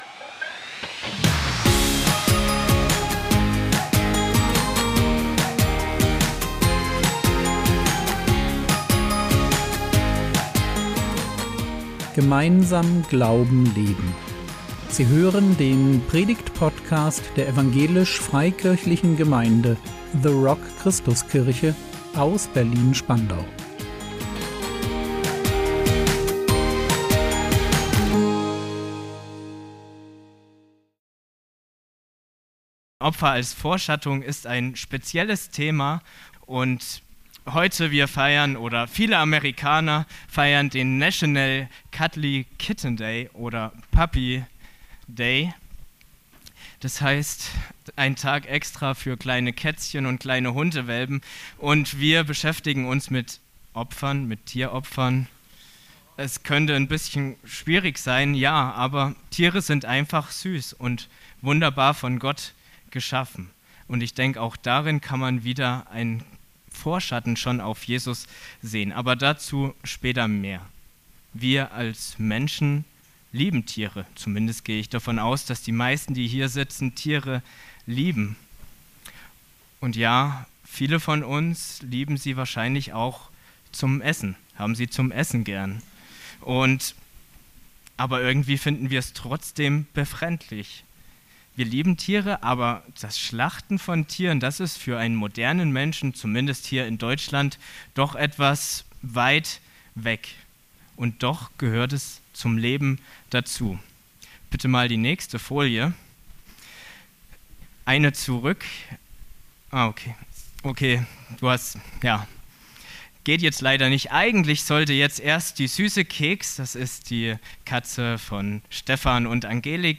Opfer als Vorschattung | 23.03.2025 ~ Predigt Podcast der EFG The Rock Christuskirche Berlin Podcast
Predigtschulung